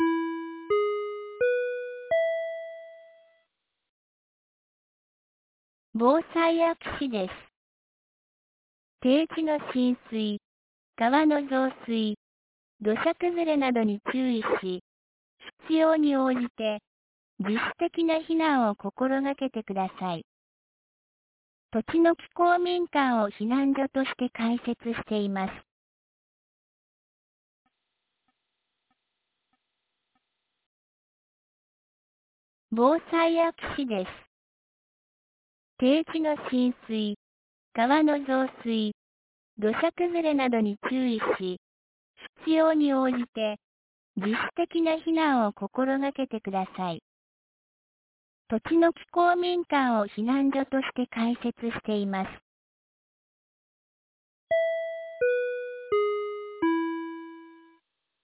2023年06月02日 10時20分に、安芸市より栃ノ木へ放送がありました。
放送音声